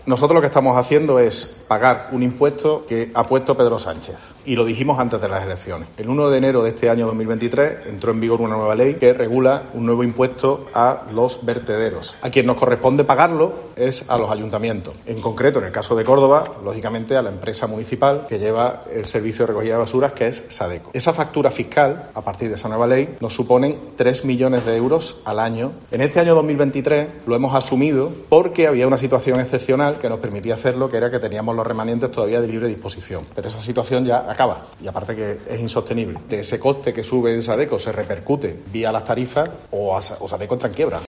Así lo ha detallado el regidor en una rueda de prensa, en la que ha aclarado que "esa factura fiscal que ha puesto el Gobierno de la Nación desde el 1 de enero suponen tres millones de euros al año, de un impuesto que al final vamos a terminar pagando todos los cordobeses o no puede ser de otra forma, porque si no sería insostenible" la empresa.